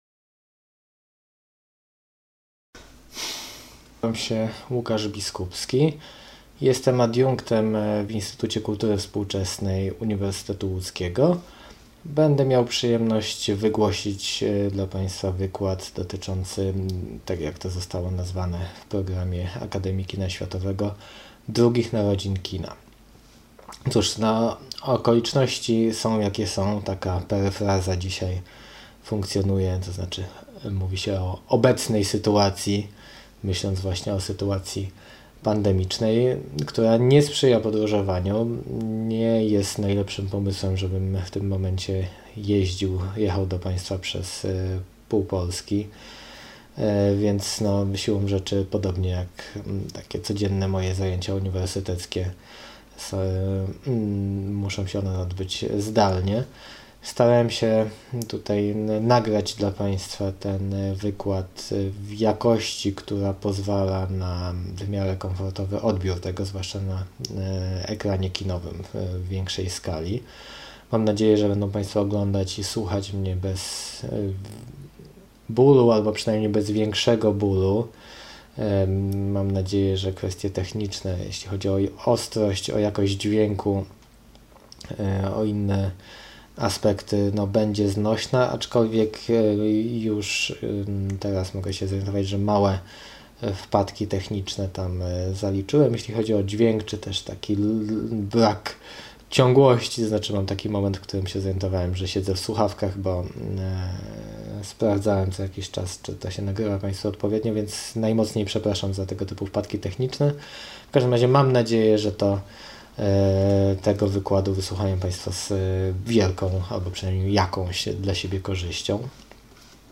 Wykład z 6 października 2020